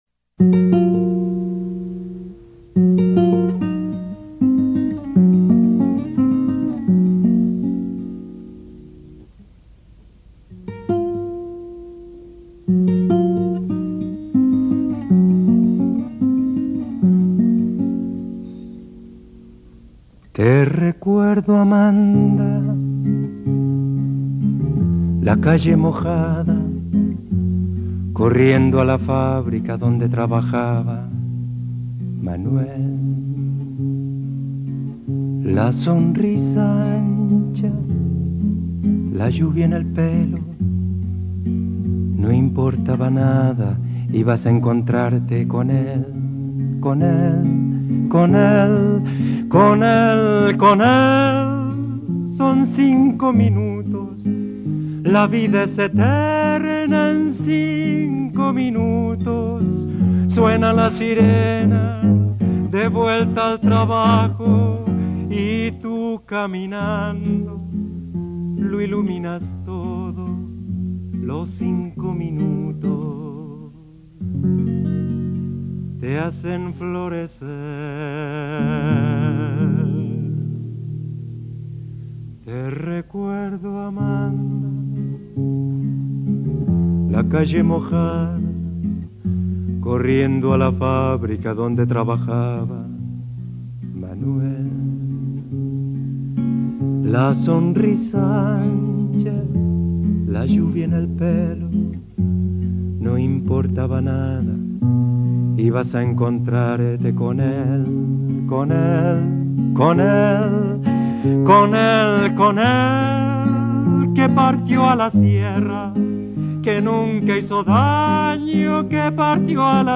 zpracování pro peruánskou televizi.